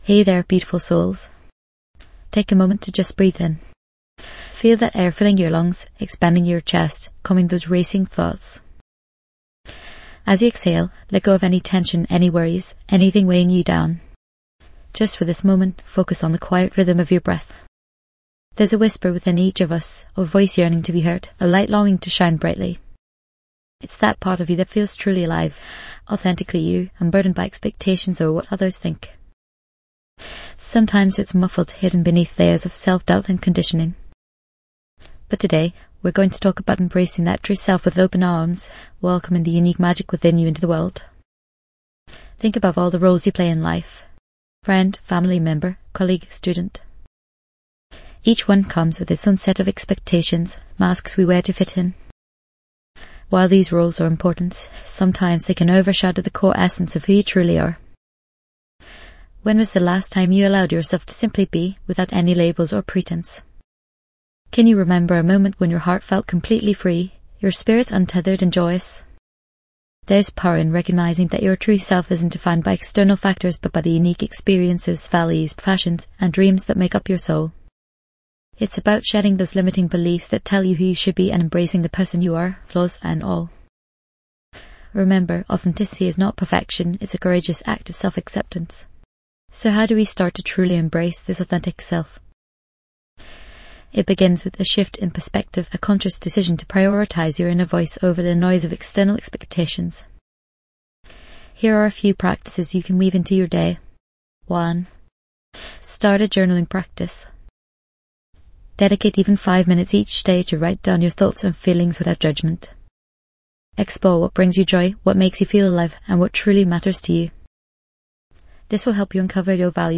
Dive deep into self-discovery and acceptance with this empowering guided meditation. Learn to let go of judgments and embrace your true self, fostering a profound sense of inner peace and personal growth.